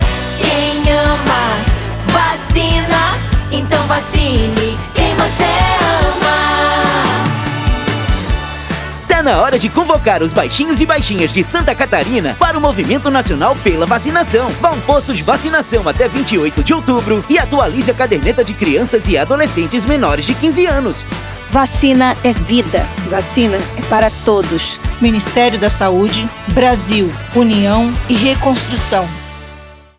Áudio - Spot 30seg - Campanha de Multivacinação em Santa Catarina - 1,1mb .mp3